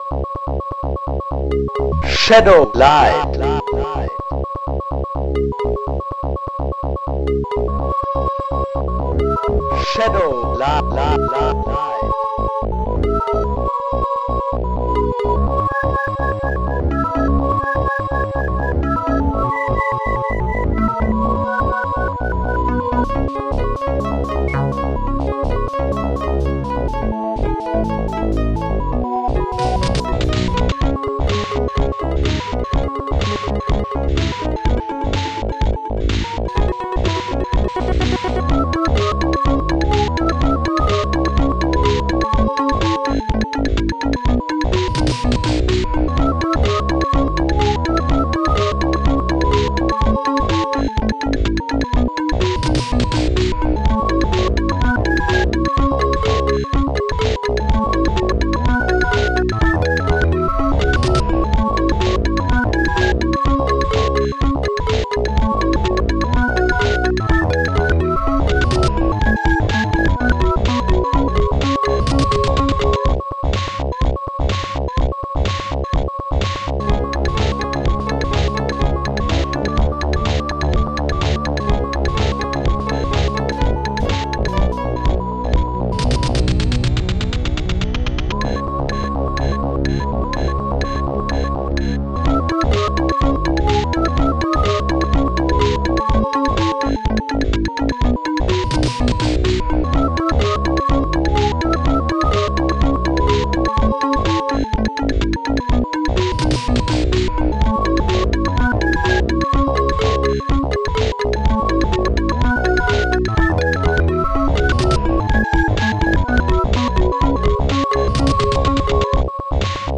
SoundTracker Module